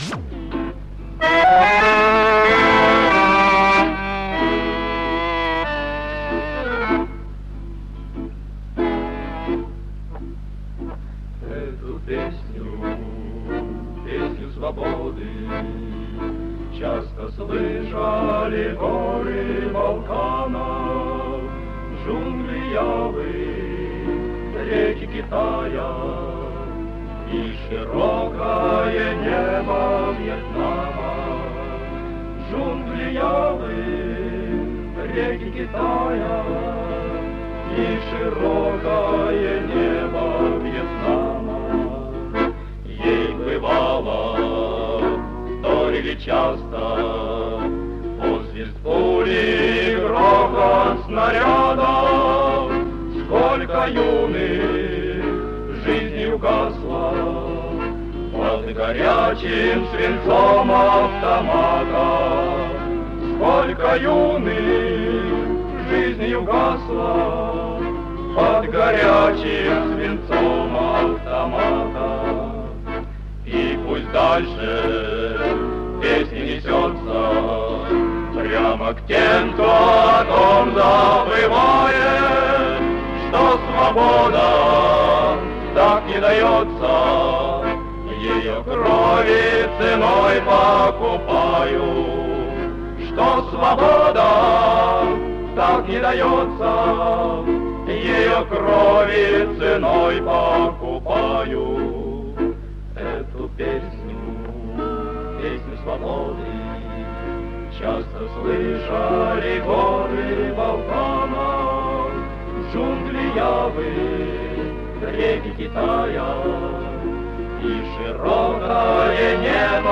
ВТОРОЙ МОСКОВСКИЙ ВЕЧЕР-КОНКУРС СТУДЕНЧЕСКОЙ ПЕСНИ
Мужской ансамбль МГУ